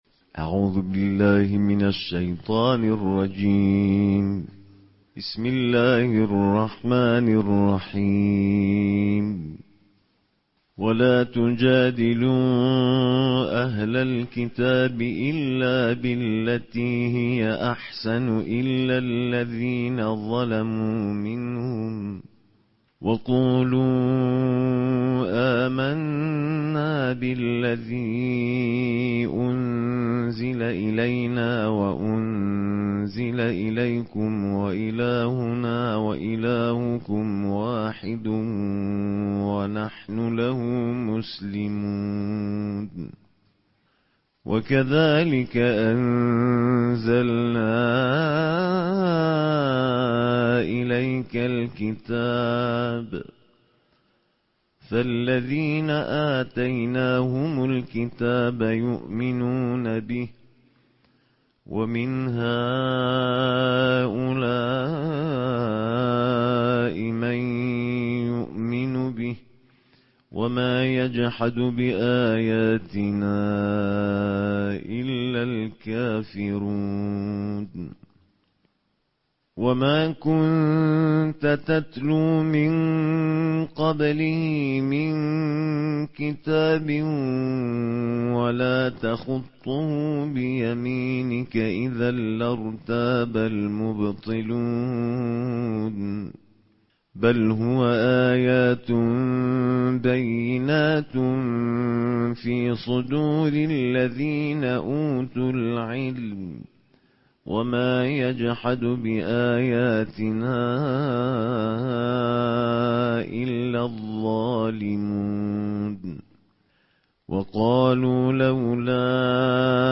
Чтение 21 джуза Корана голосами международных чтецов + аудио